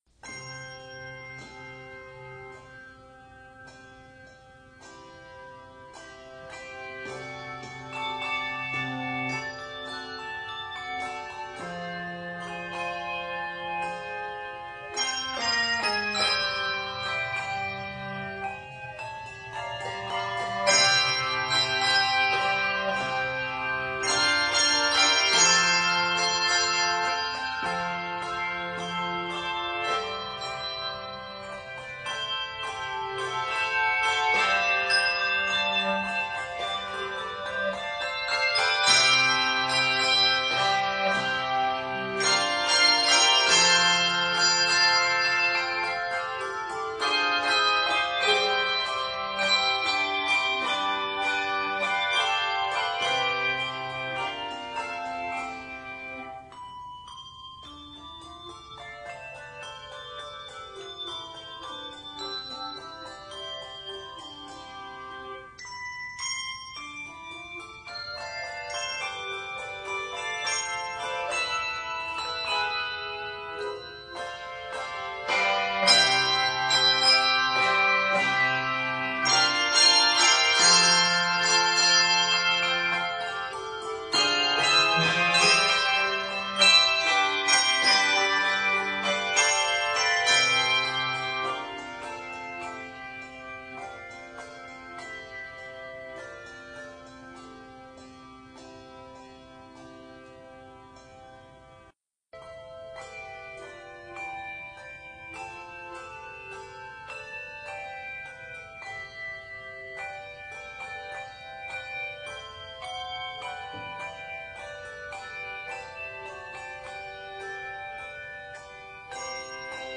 Review: A good arrangement of the popular gospel tune.